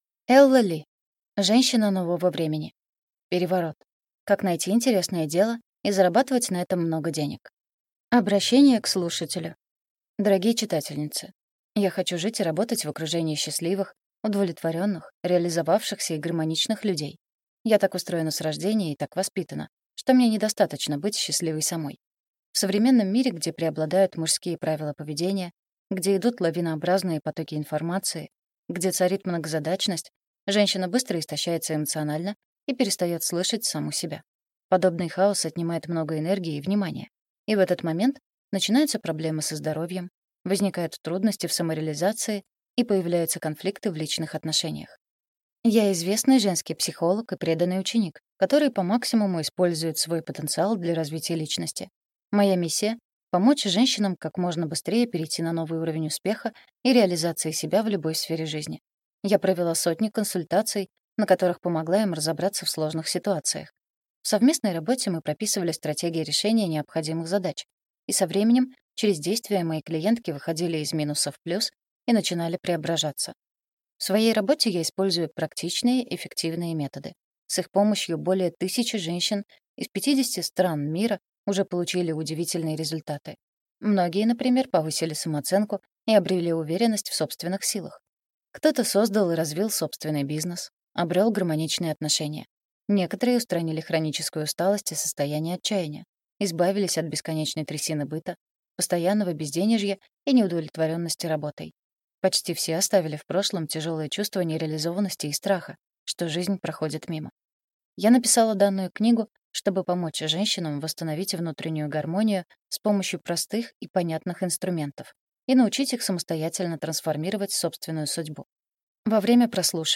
Аудиокнига Женщина нового времени. Переворот. Как найти интересное дело и зарабатывать на этом много денег | Библиотека аудиокниг